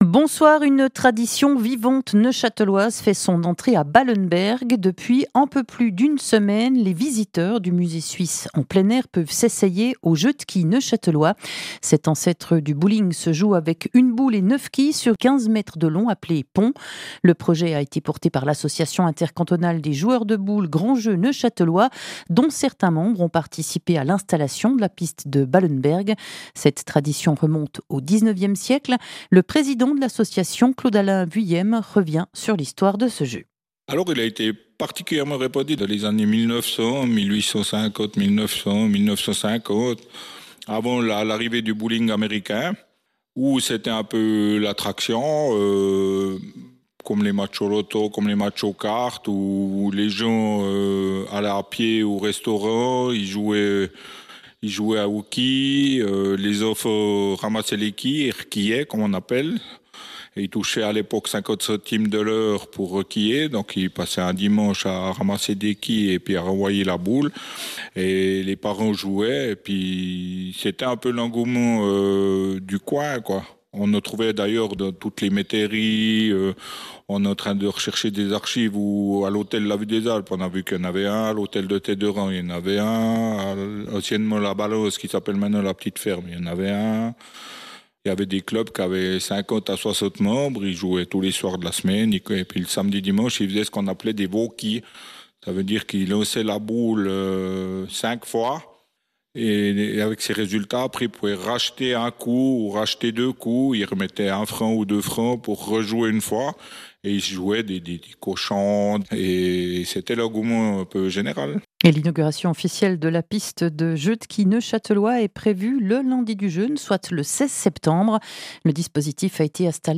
Interviews
Version audio 2 (journal soir)